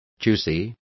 Complete with pronunciation of the translation of choosy.